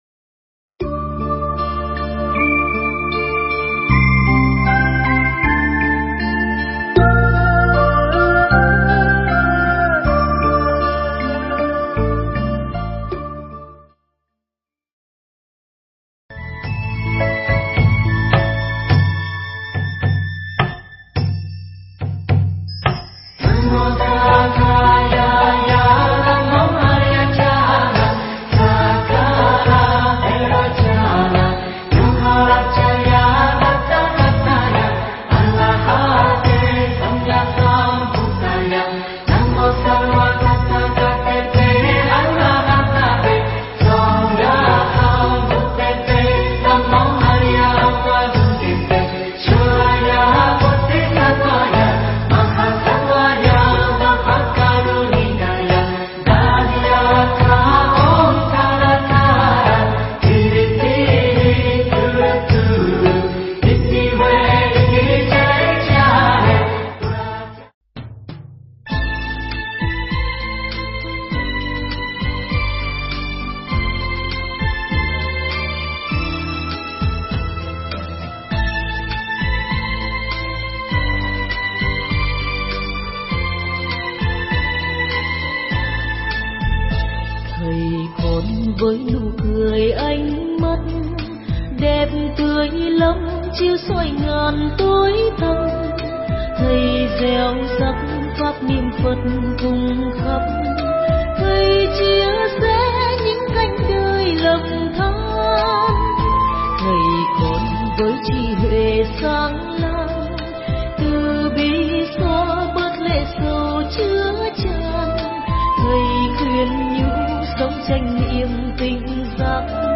Nghe Mp3 thuyết pháp Hãy Bảo Vệ Mầm Sống